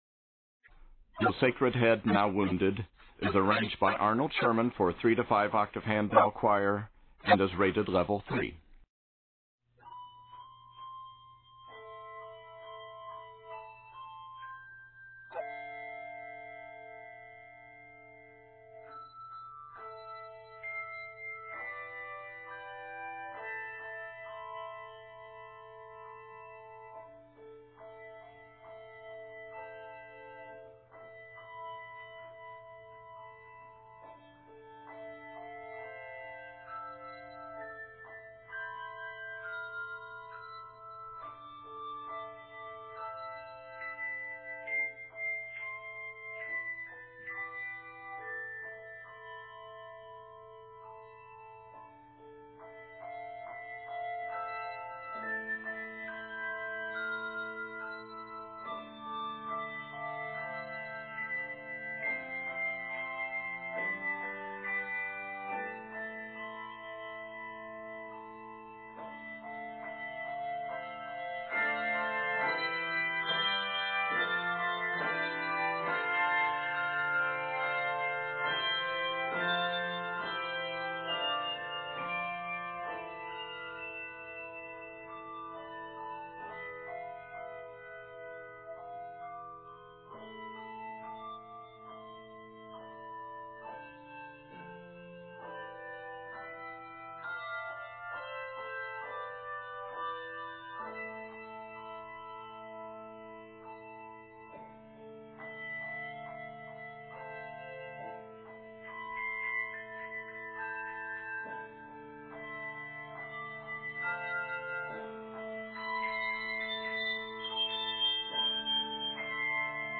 Sensitive, passionate, and stirring
Octaves: 3-5